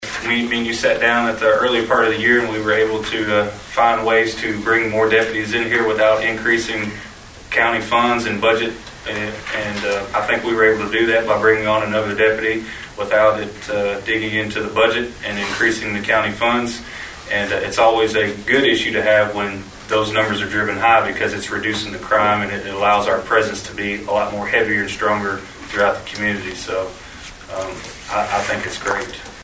And that’s according to Sheriff Aaron Acree, who Monday night at fiscal court gave a comprehensive year-in-review to Trigg County’s magistrates and their judge-executive, Stan Humphries.